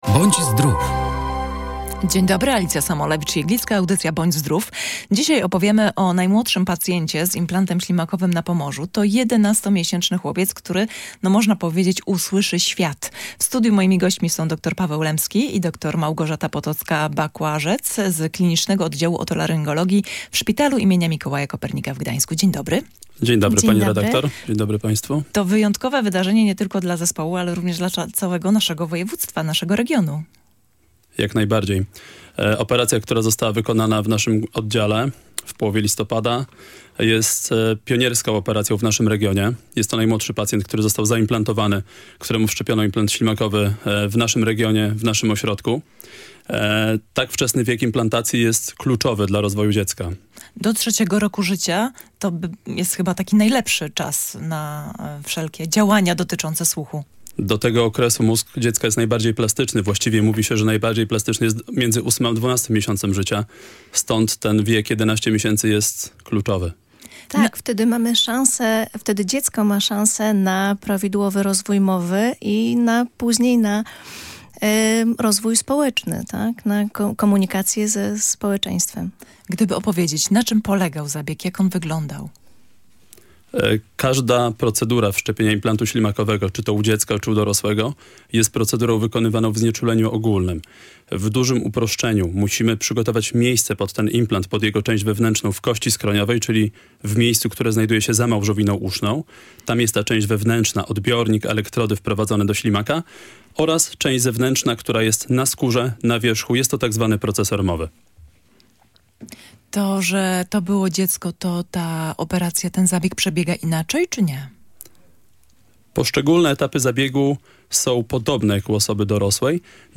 O tym opowiadali specjaliści podczas audycji.